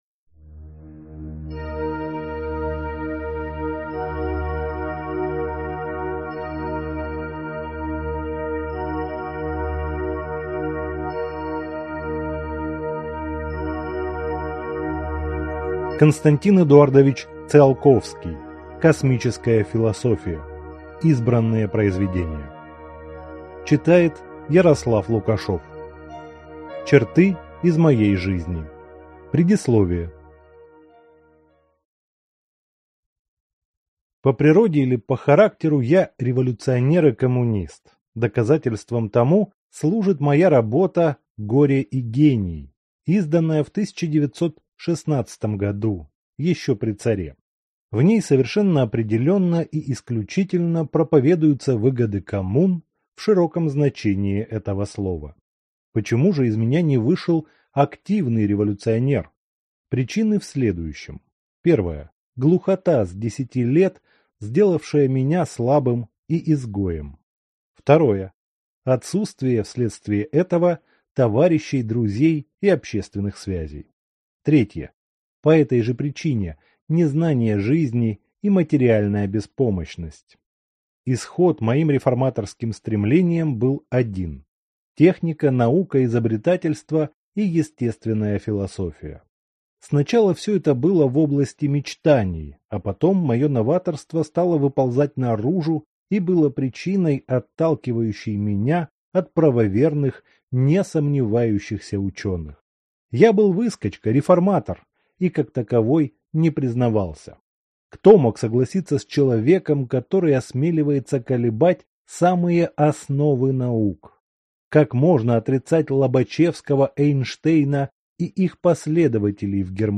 Аудиокнига Космическая философия. Избранные произведения | Библиотека аудиокниг